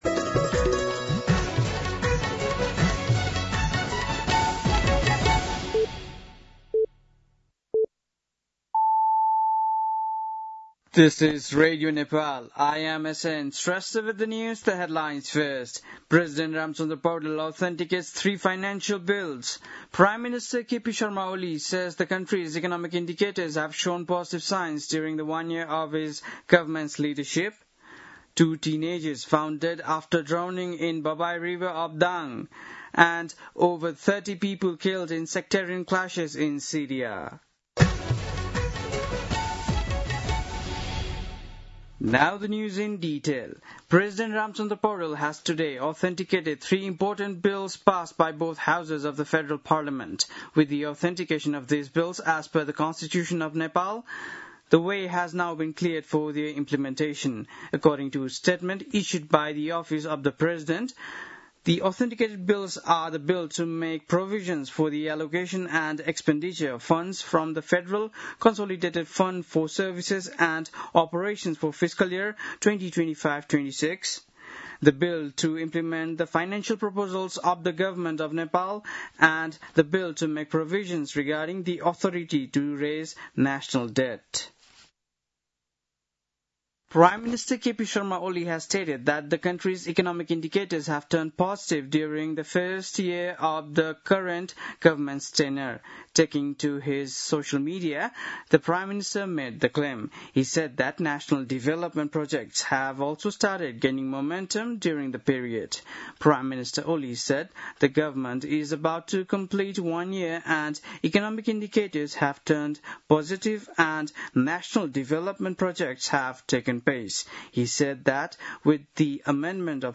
बेलुकी ८ बजेको अङ्ग्रेजी समाचार : ३० असार , २०८२
8-pm-english-news-3-30.mp3